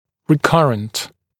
[rɪ’kʌrənt][ри’карэнт]рецидивирующий, повторяющийся, возвратный